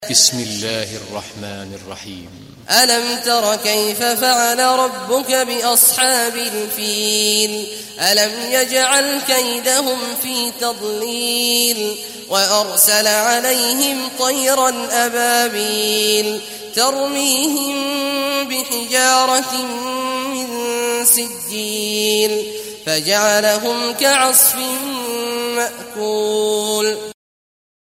Fil Suresi İndir mp3 Abdullah Awad Al Juhani Riwayat Hafs an Asim, Kurani indirin ve mp3 tam doğrudan bağlantılar dinle